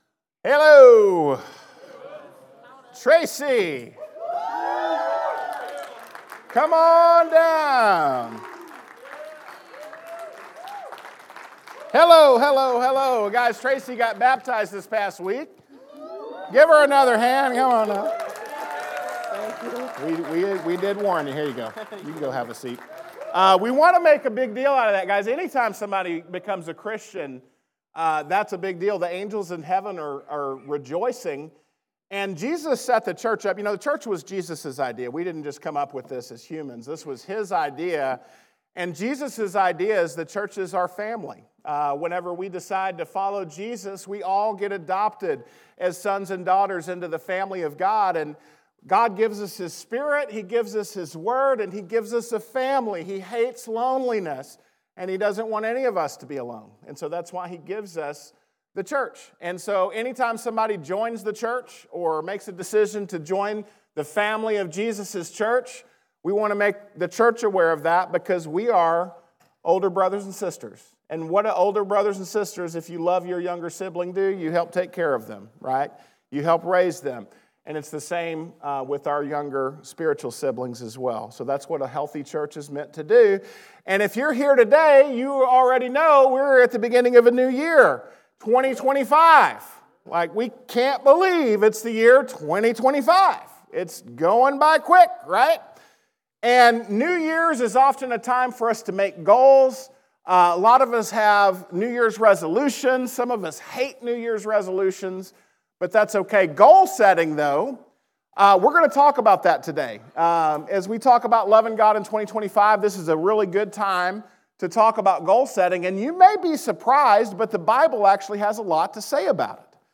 Lesson presented at The Crossings Church Collinsville in Collinsville, IL – a non-denominational church that meets Sunday mornings at 2002 Mall Street, Collinsville, IL just outside of St. Louis.